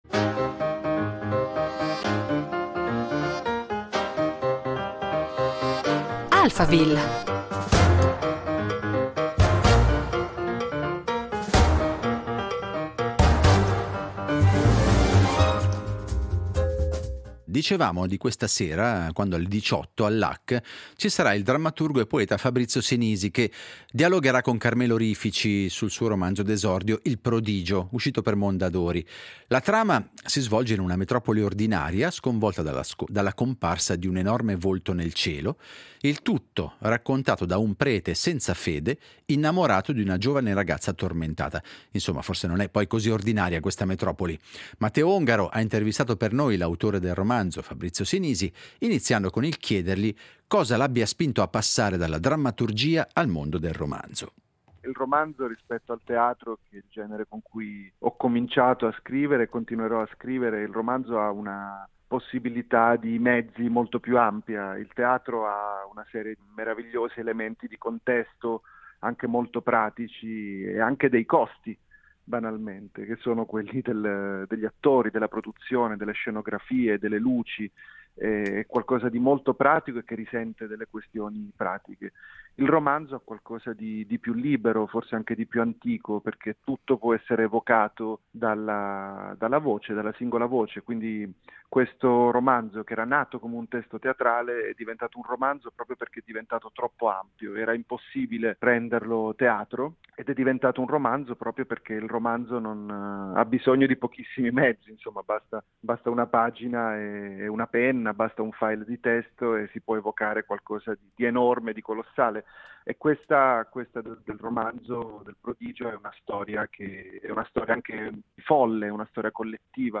Alphaville